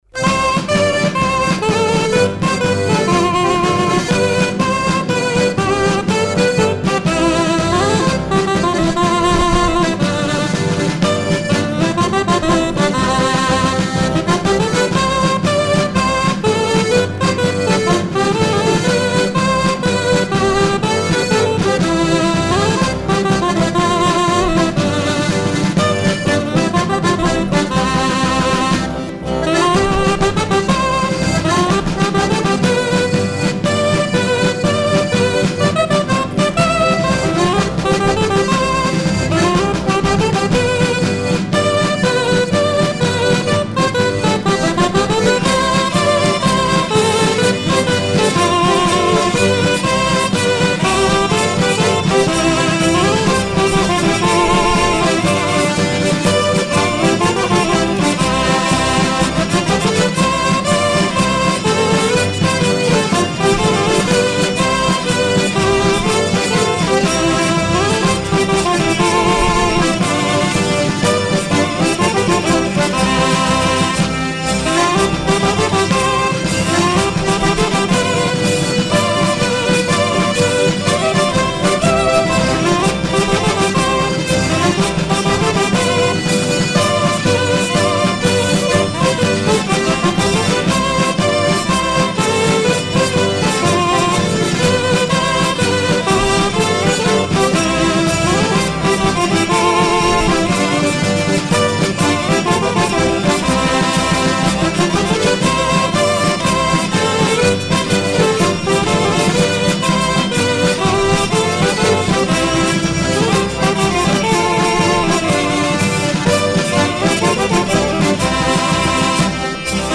air de cri de marchand de glace
Pièce musicale éditée